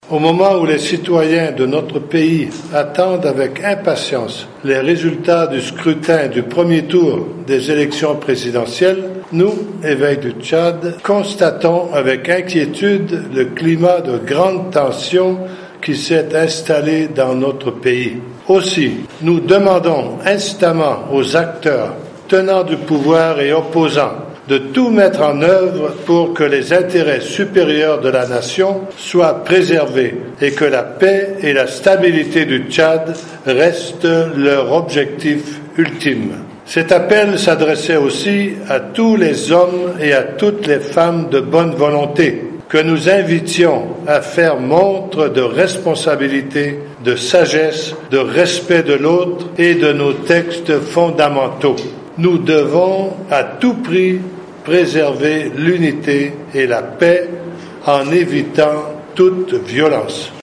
Le président de la conference épiscopale du Tchad Monseigneur Jean-Claude Bouchard au micro